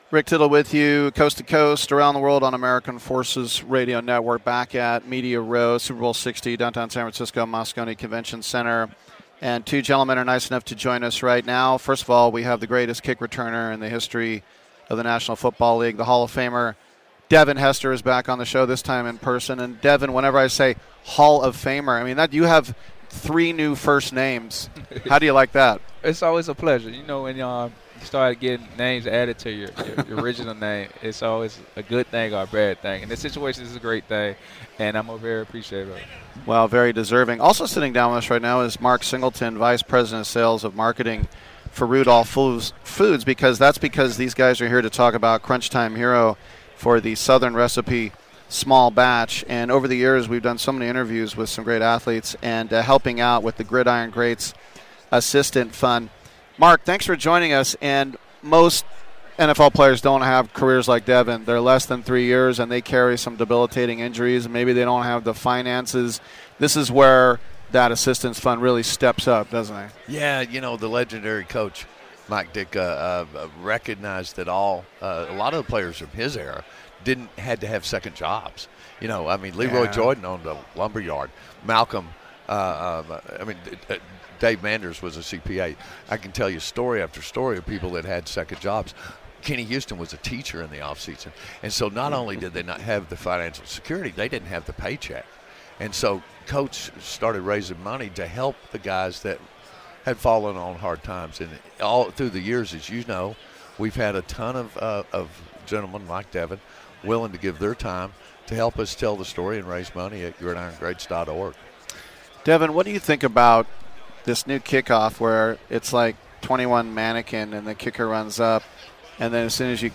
Live From Super Bowl LX Media Row: Hall of Fame Return Specialist Devin Hester